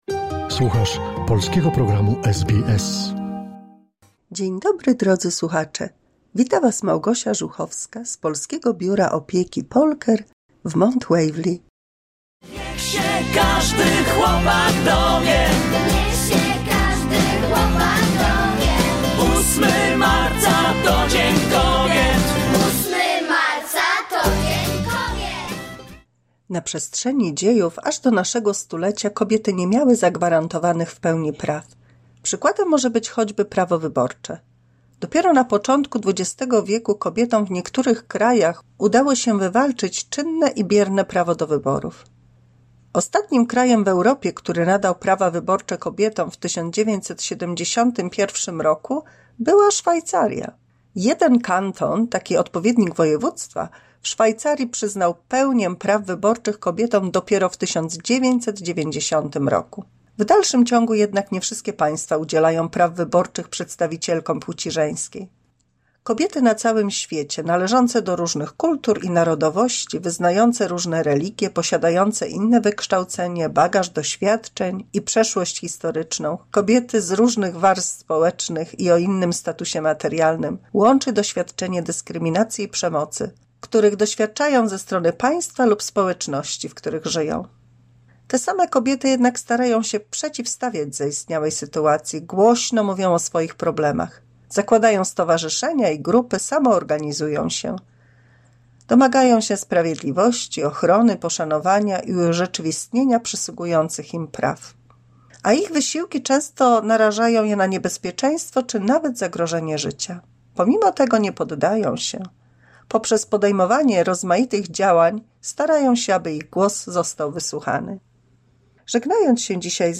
196 słuchowisko dla polskich seniorów